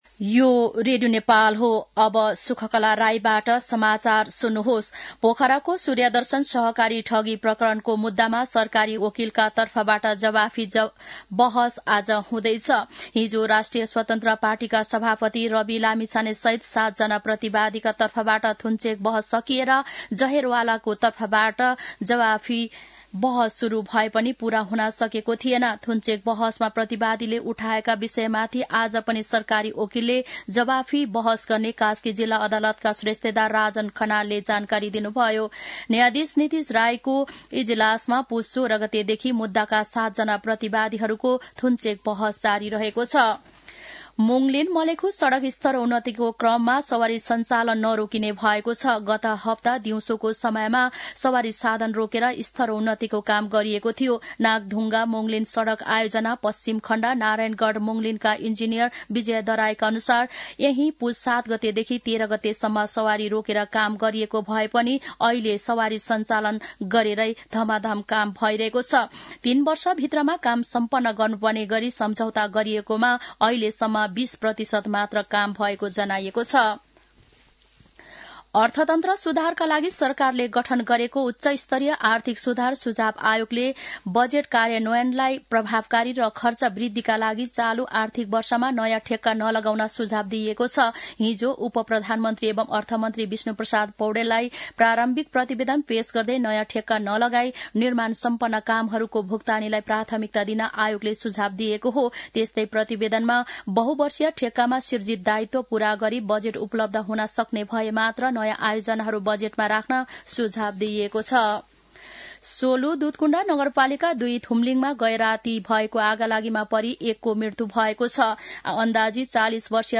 दिउँसो १ बजेको नेपाली समाचार : २६ पुष , २०८१
1-pm-news-1-3.mp3